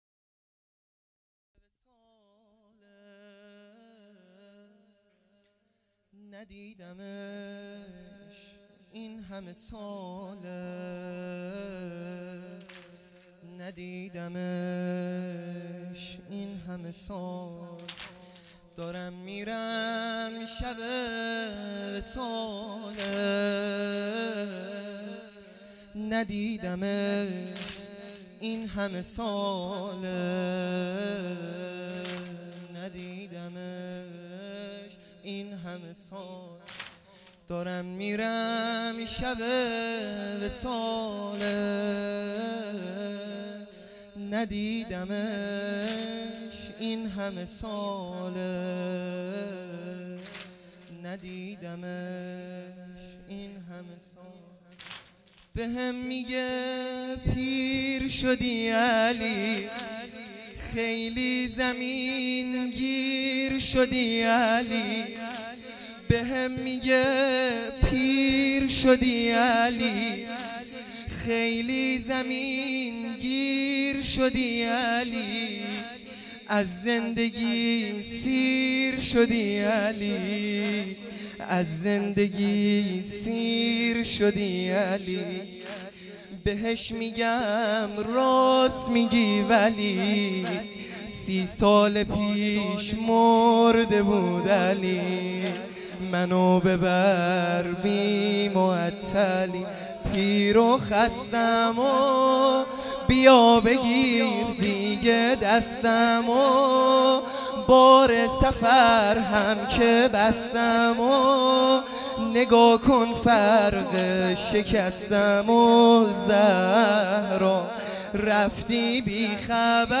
سنگین | از زندگی سیر شدی علی
شب ۲۱ رمضان المبارک